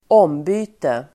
Uttal: [²'åm:by:te]